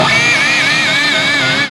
GTR HORSE04L.wav